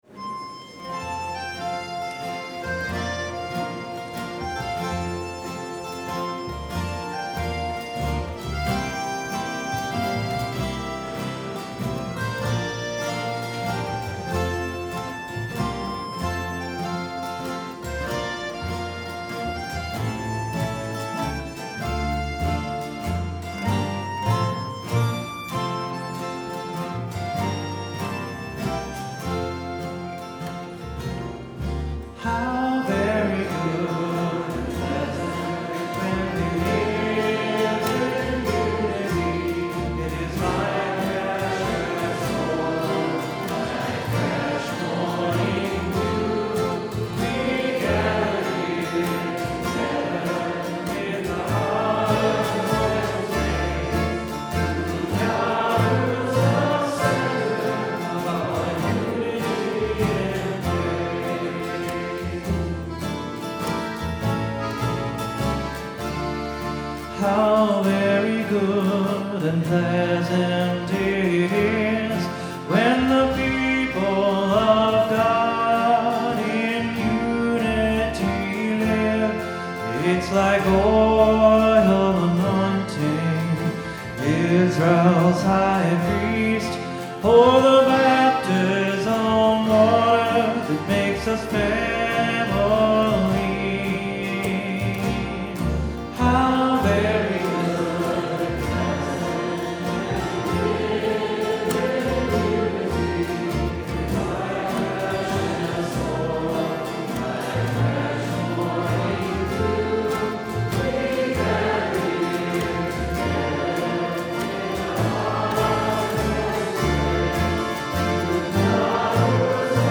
How about a mix of Caucasians and Koreans playing Black Gospel on guitars?